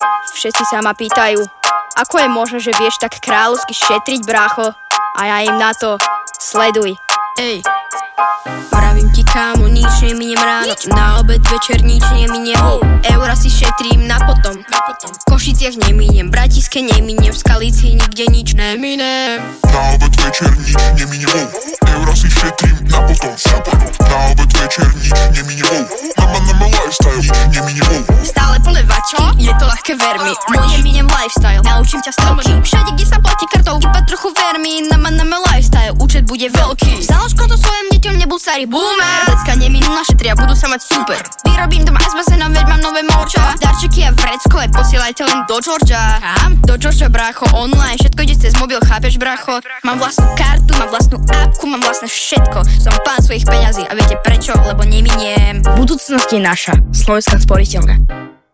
rapovú hudbu a hip hopový tanec.